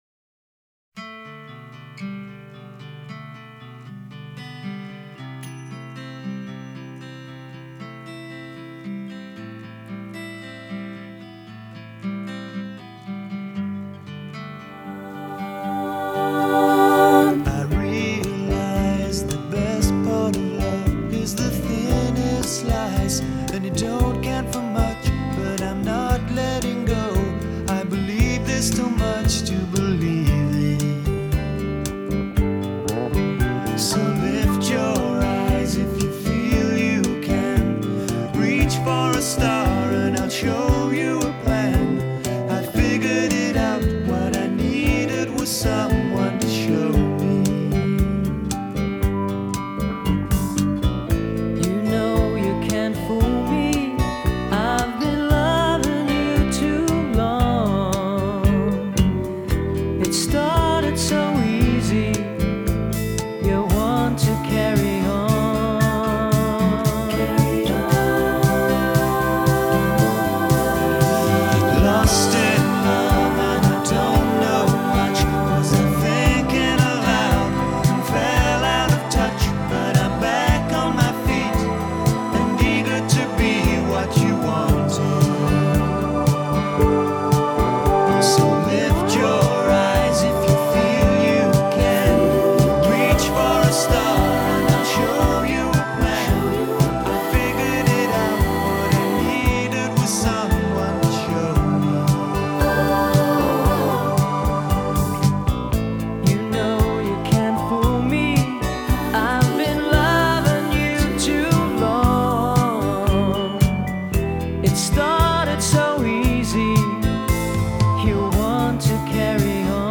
una romántica y deliciosa melodía